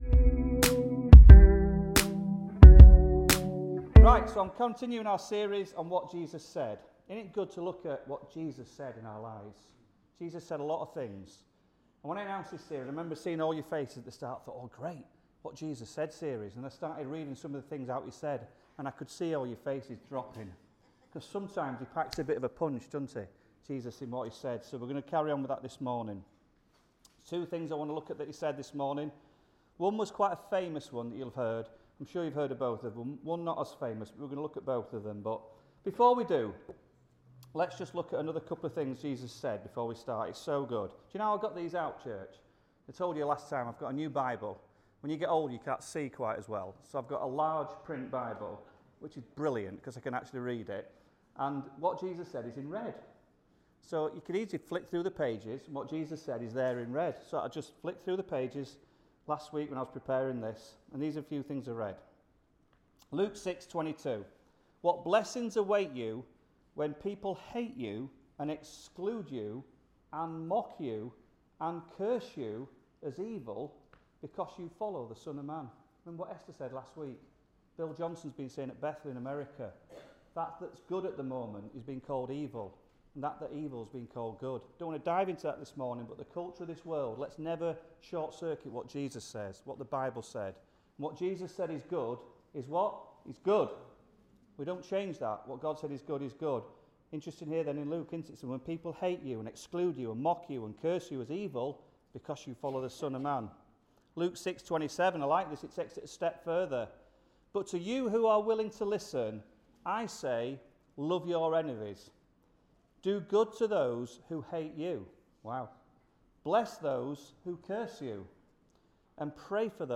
Sunday Messages What Jesus Said Part 2